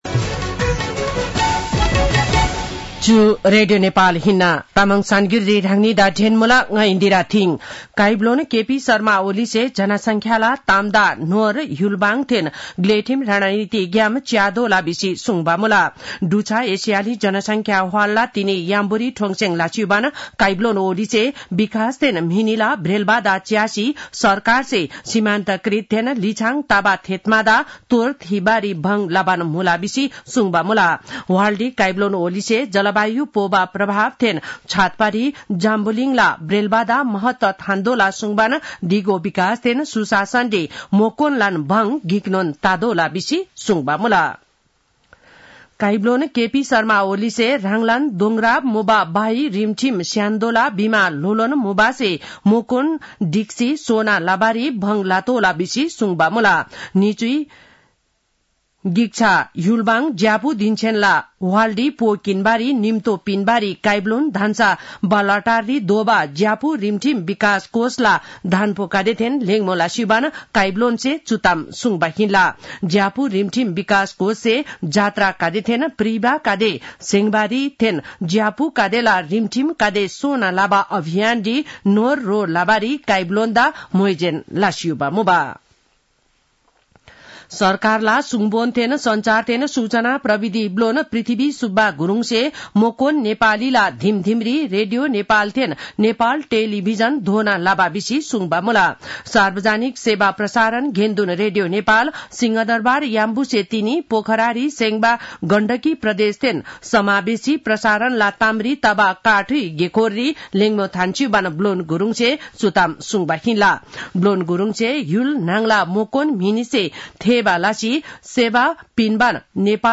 तामाङ भाषाको समाचार : १४ मंसिर , २०८१
Tamang-news-8-12.mp3